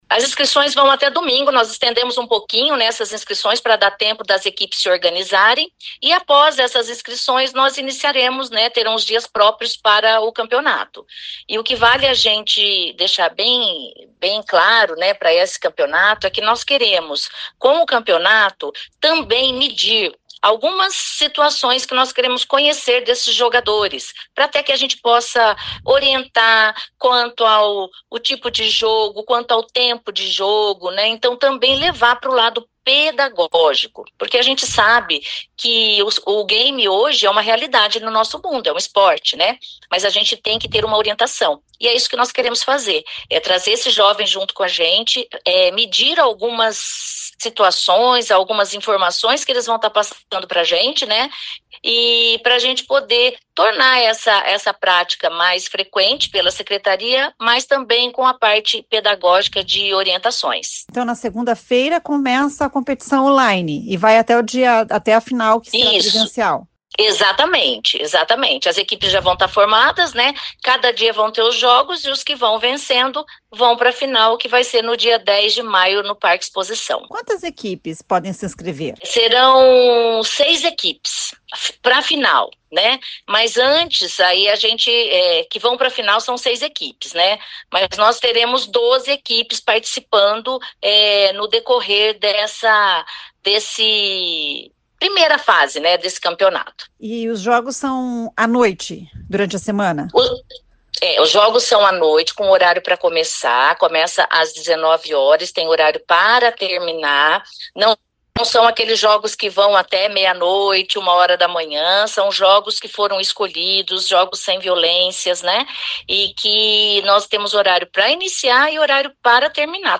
A secretária da Juventude, Sandra Franchini, explica como participar.